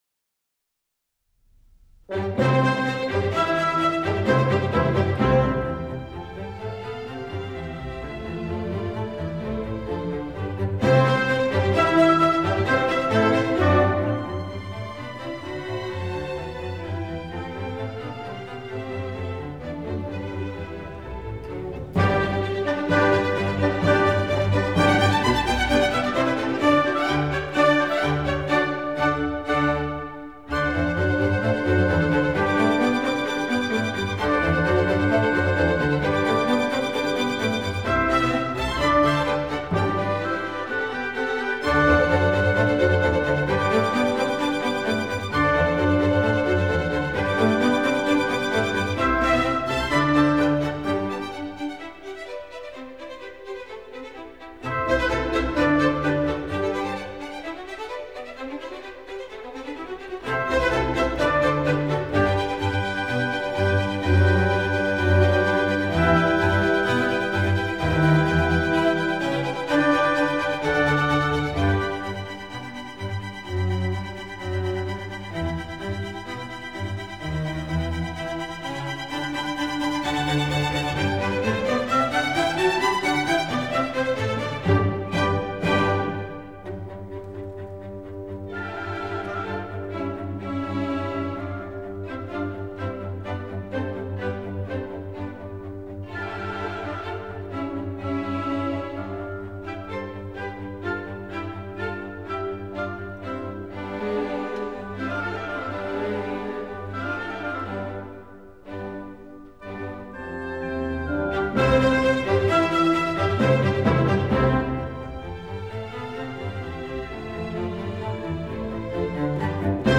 » 1 - Symphonies